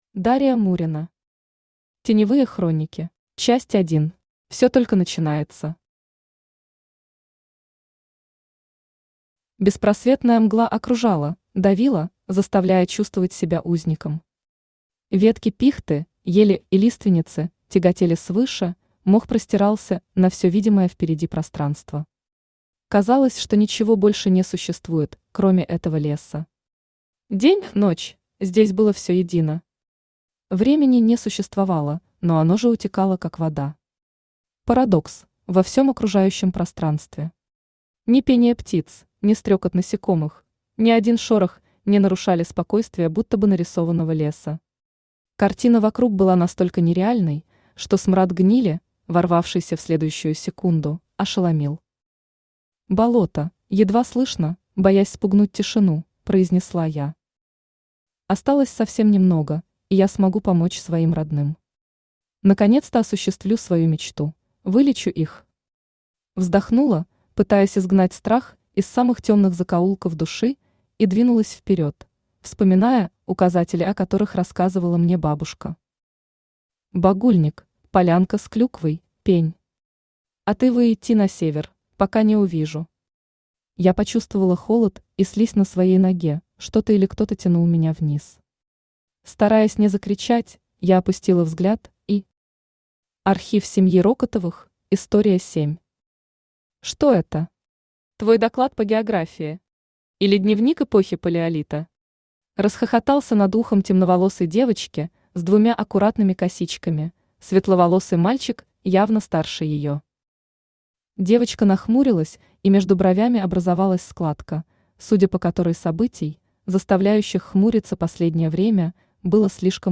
Aудиокнига Теневые хроники Автор Дария Мурина Читает аудиокнигу Авточтец ЛитРес.